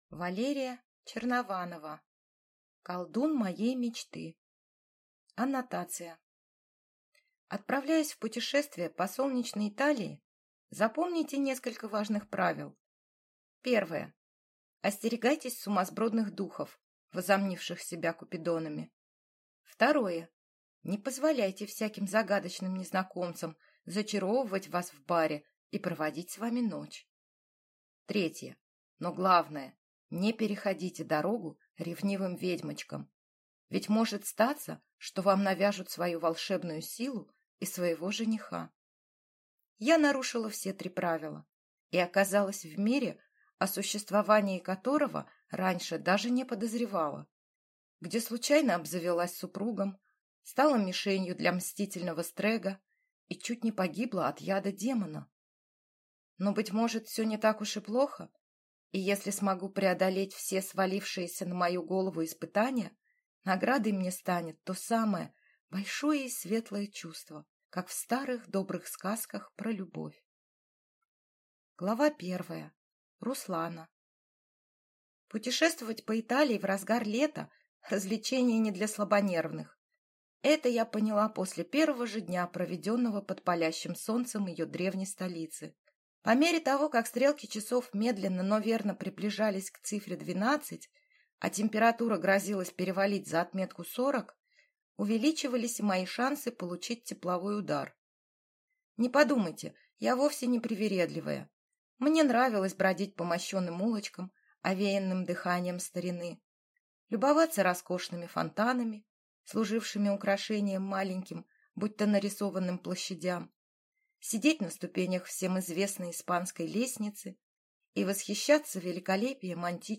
Аудиокнига Колдун моей мечты | Библиотека аудиокниг